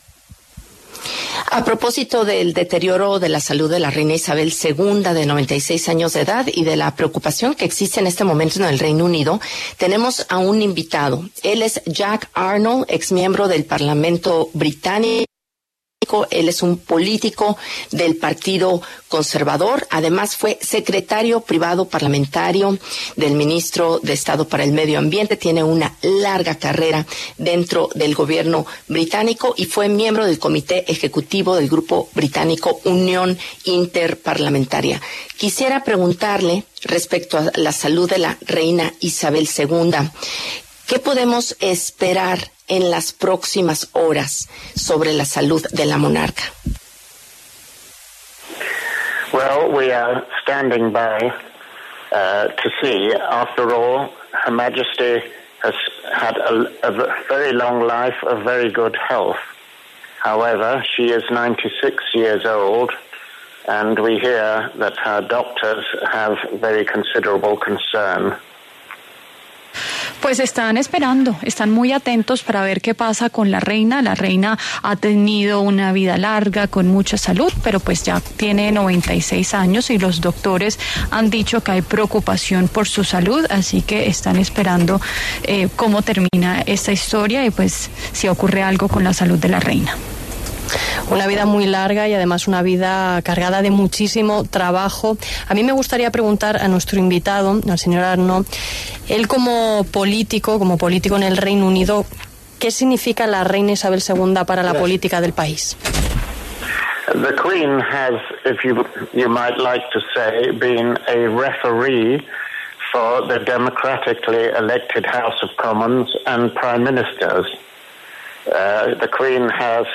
Jacques Arnold, exmiembro del Parlamento Británico, se pronunció en La W sobre la preocupación por la salud de la reina Isabel II.